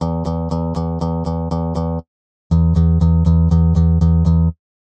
繝輔Ο繝ｳ繝医ｻ繝斐ャ繧ｯ繧｢繝繝励ｒ繝悶Μ繝繧ｸ蟇繧翫√ロ繝繧ｯ蟇繧翫↓縺励◆髻ｳ濶ｲ縺ｧ縺吶よ怙蛻昴ｮ貍泌･上ｯ繝輔Ο繝ｳ繝医ｻ繝斐ャ繧ｯ繧｢繝繝励′繝悶Μ繝繧ｸ蟇繧翫↓縺ゅｋ縺溘ａ縲√い繧ｿ繝繧ｯ諢溘′蠑ｷ縺上∵守椚縺ｪ髻ｳ縺ｫ縺ｪ縺｣縺ｦ縺縺ｾ縺吶
谺｡縺ｮ貍泌･上ｯ繝輔Ο繝ｳ繝医ｻ繝斐ャ繧ｯ繧｢繝繝励′繝阪ャ繧ｯ蟇繧翫↓縺ゅｋ縺溘ａ縲√い繧ｿ繝繧ｯ縺梧沐繧峨°縺上∽ｸｸ縺ｿ縺ｮ縺ゅｋ髻ｳ縺ｫ縺ｪ縺｣縺ｦ縺縺ｾ縺吶